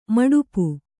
♪ maḍupu